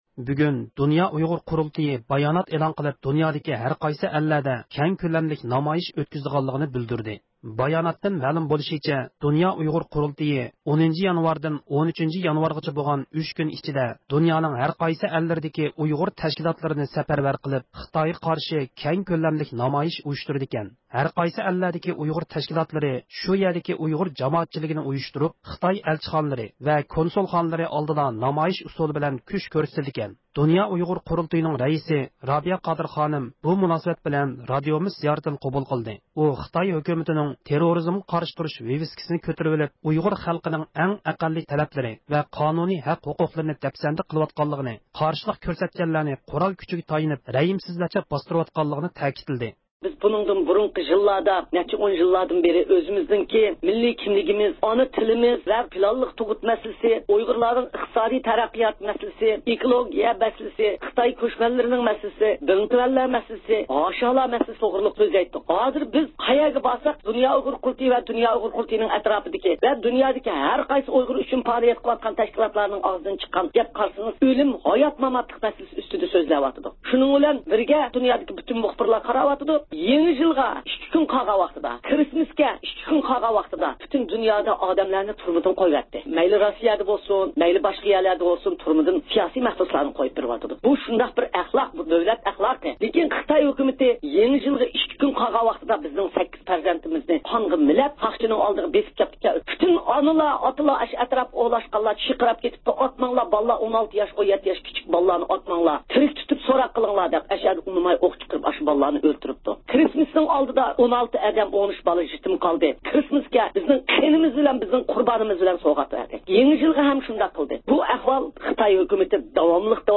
ئۇيغۇر مىللىي ھەرىكىتىنىڭ رەھبىرى، دۇنيا ئۇيغۇر قۇرۇلتىيىنىڭ رەئىسى رابىيە قادىر خانىم بۇ مۇناسىۋەت بىلەن رادىئومىز زىيارىتىنى قوبۇل قىلدى.